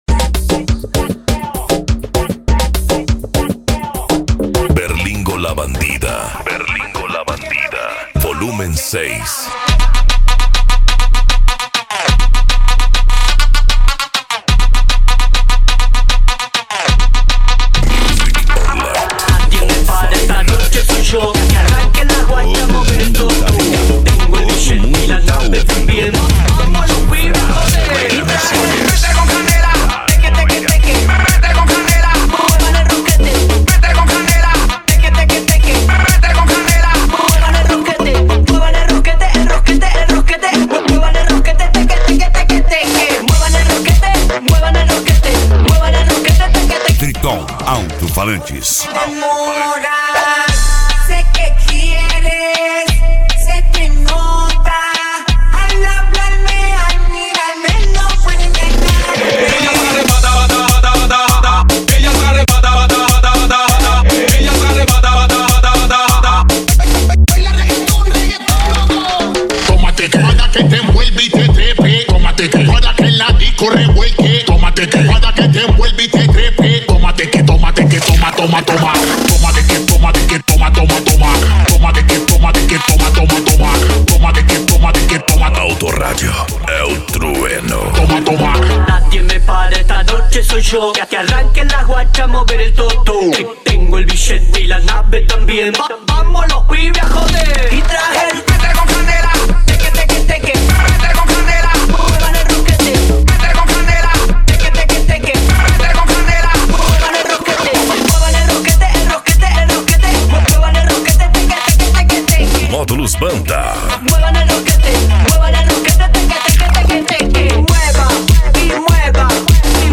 Funk
Mega Funk
Remix